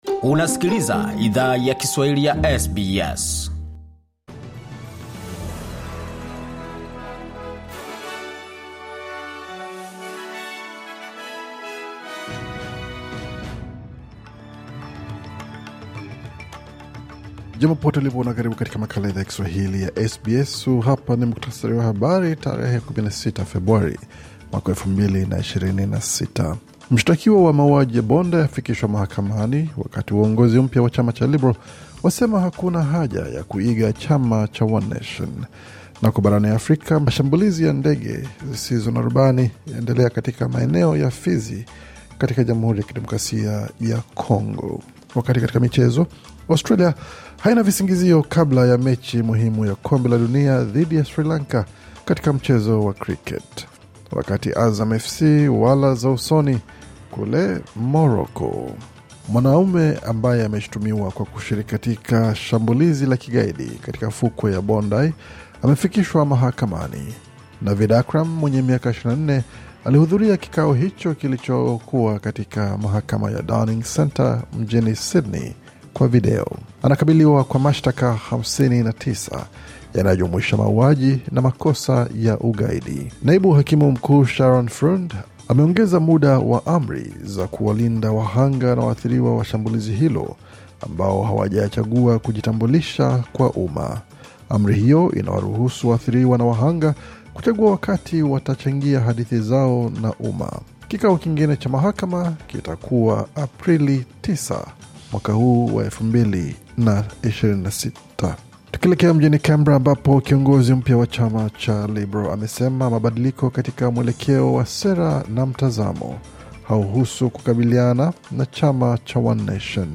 Taarifa ya Habari: Mshtakiwa wa mauaji ya Bondi afikishwa mahakamani